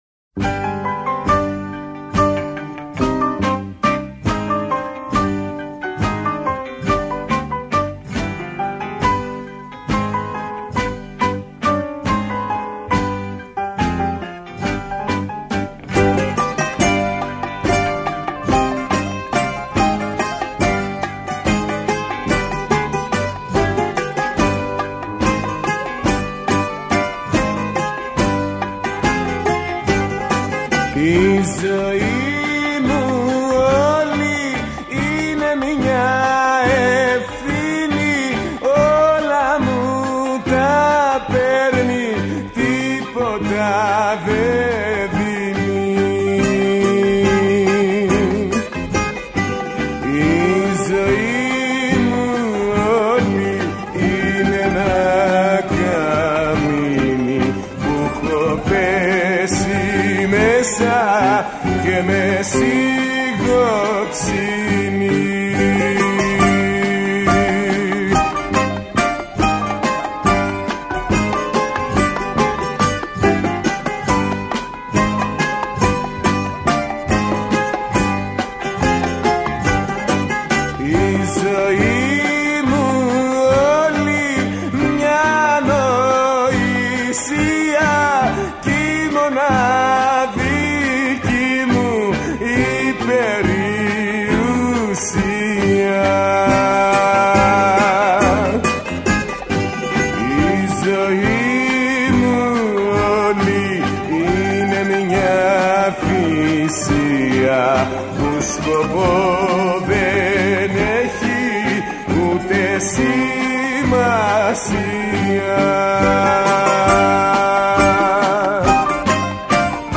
Μουσικο Αποσταγμα ΜΟΥΣΙΚΗ